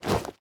equip_leather4.ogg